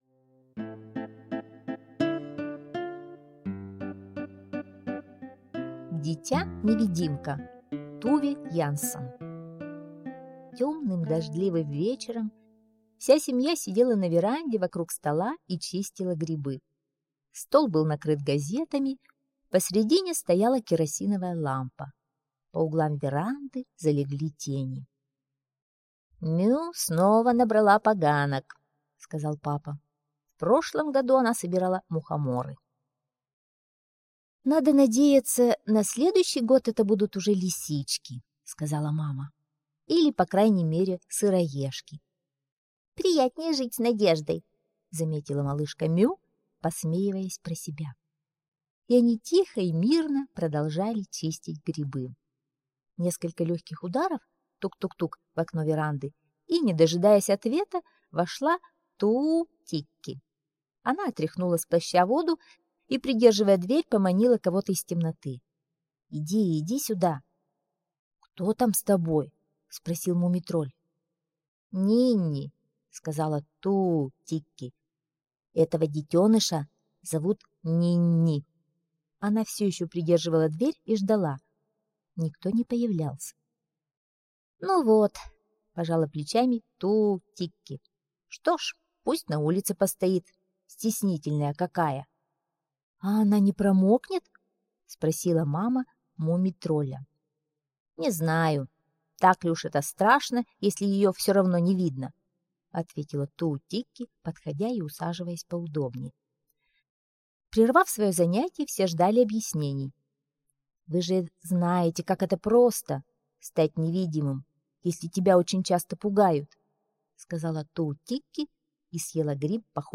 Дитя-невидимка - аудиосказка Янссон Т. Однажды темным дождливым вечером Туу-тикки привела с собой малышку Нинни, которая стала невидимой...